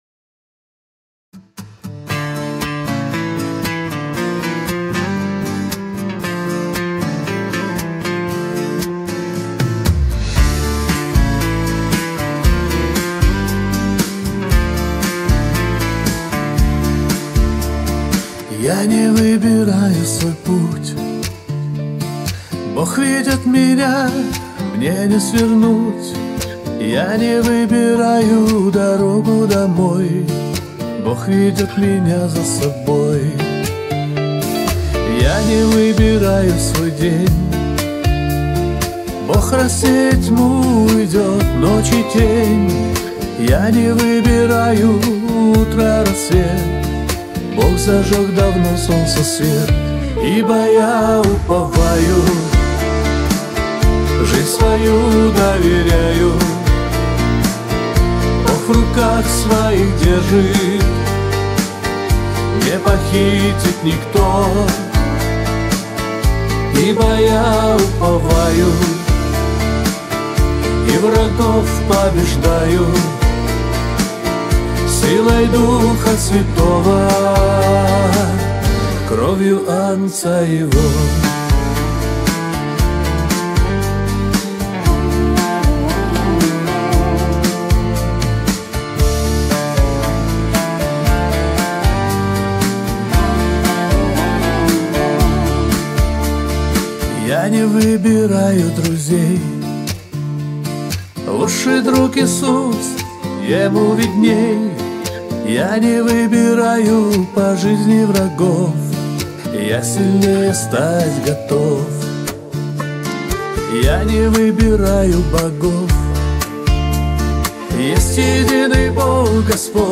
песня
72 просмотра 129 прослушиваний 22 скачивания BPM: 115